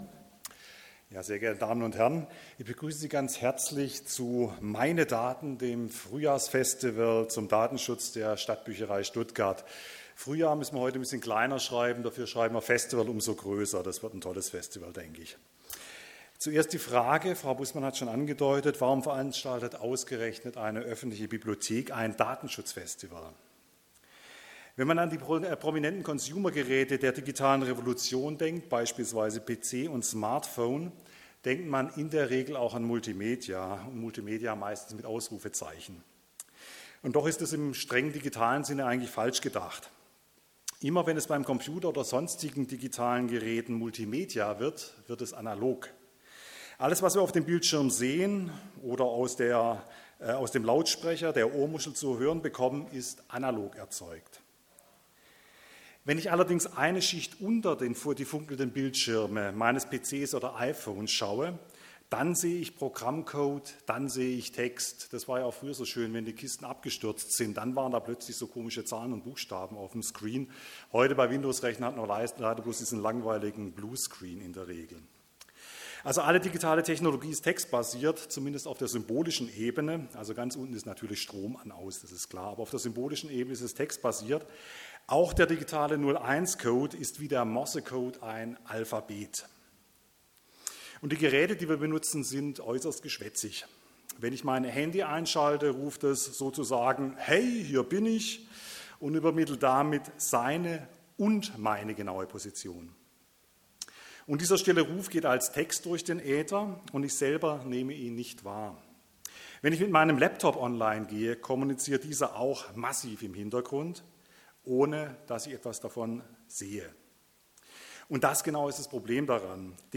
Vorträge vom Datenschutzfestival in Stuttgart
Von den Vorträgen gibt es jetzt die Mitschnitte als MP3: